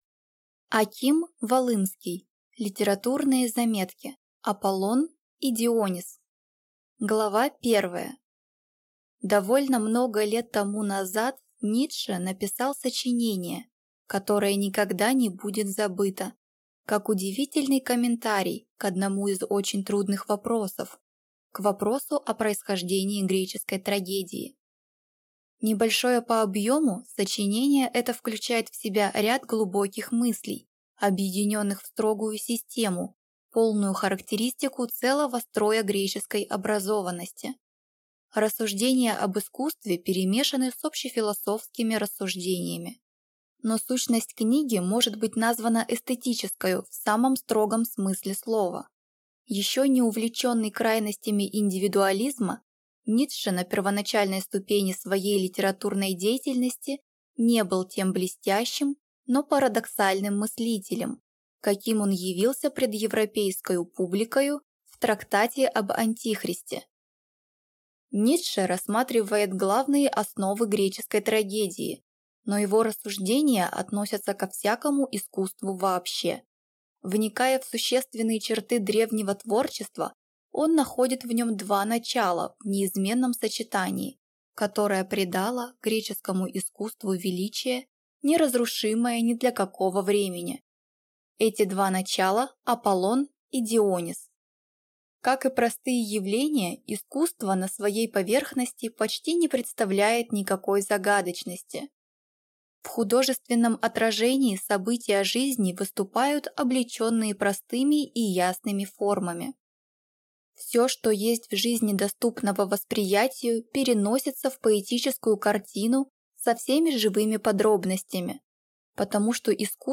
Аудиокнига Литературные заметки: Аполлон и Дионис | Библиотека аудиокниг